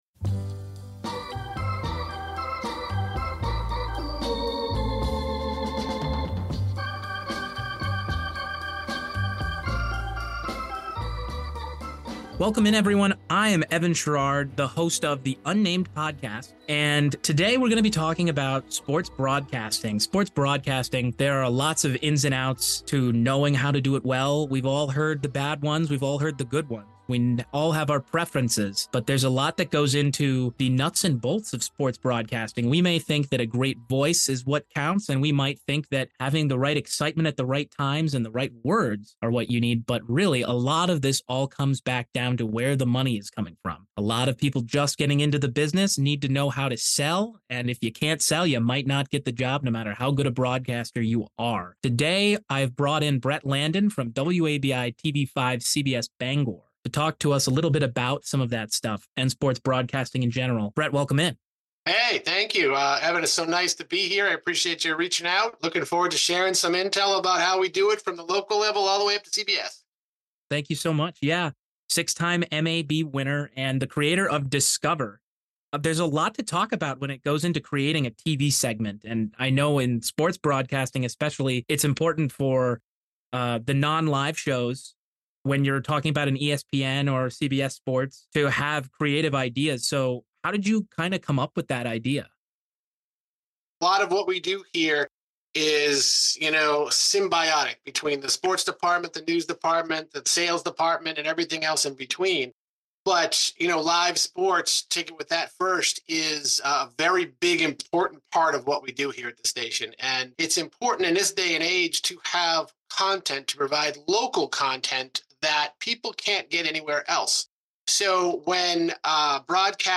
Time Stamps: Intro: 0:00 Interview Begin: 1:01 Ad Read: 10:33 Outro: 21:19